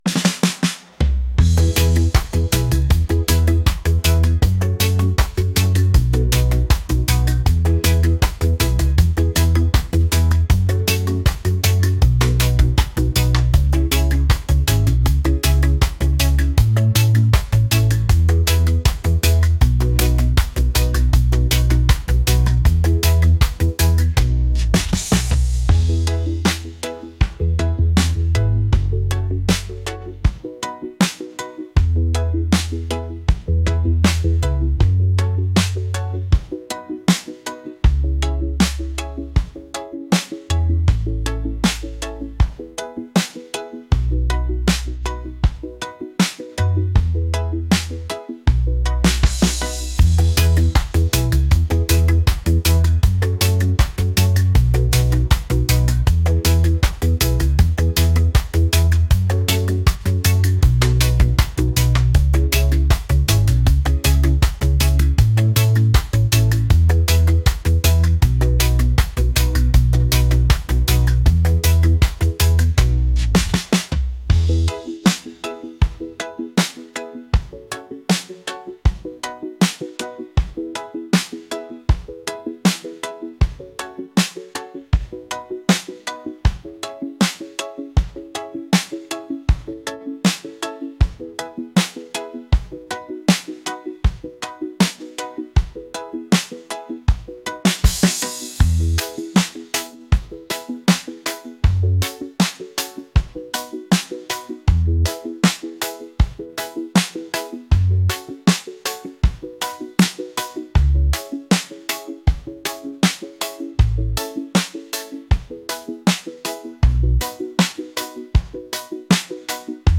upbeat | reggae